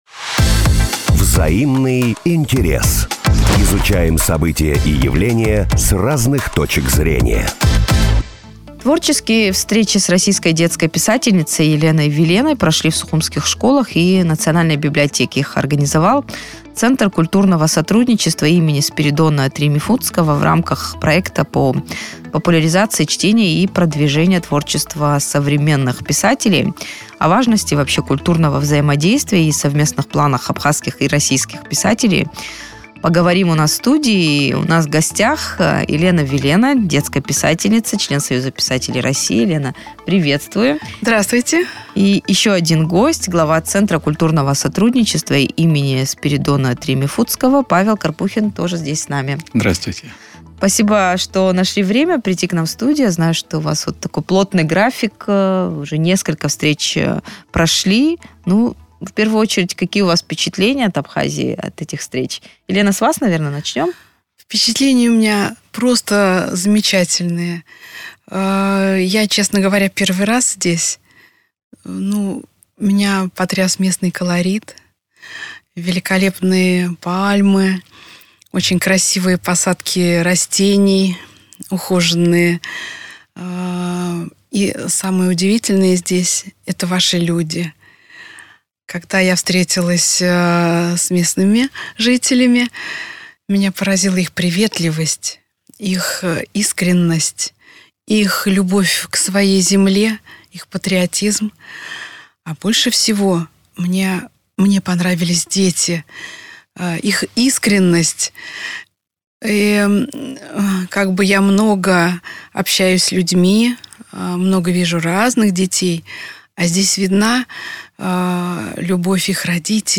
на радио Sputnik говорили с писательницей